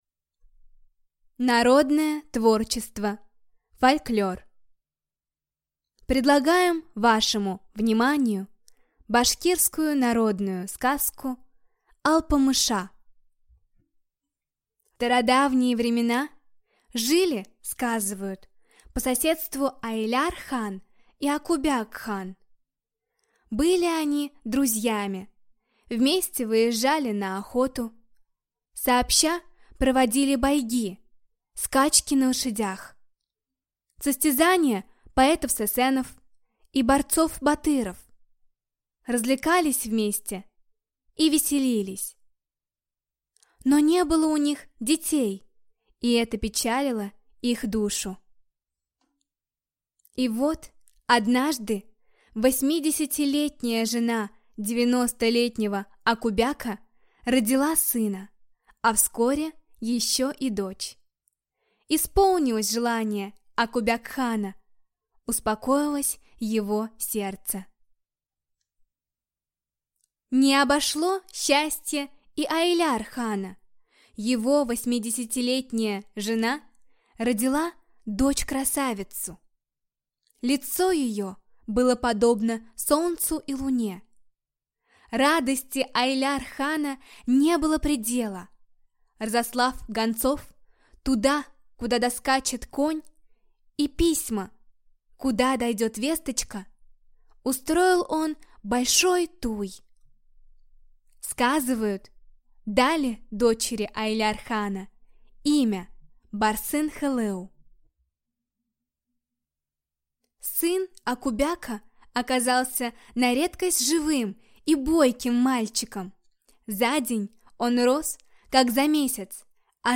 Аудиокнига Алпамыша | Библиотека аудиокниг
Читает аудиокнигу